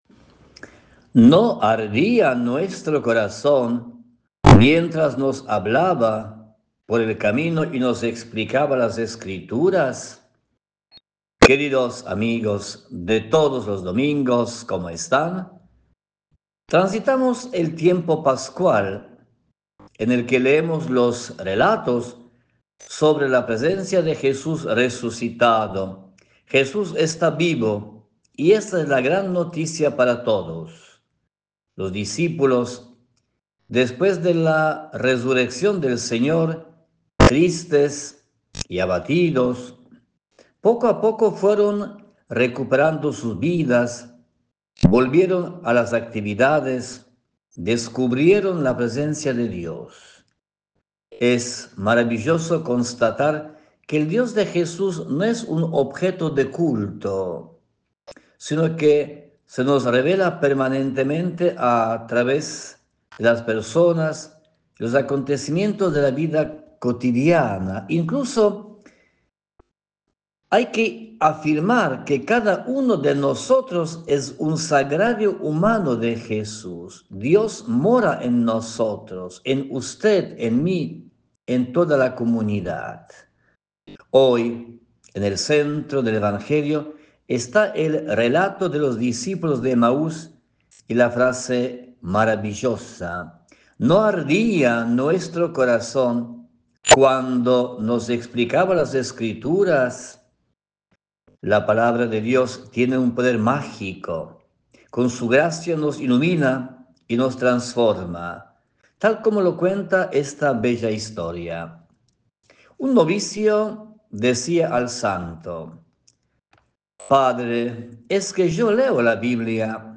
comparte cada domingo un breve y reflexivo mensaje en EME, ofreciendo una visión espiritual desde la Iglesia Católica.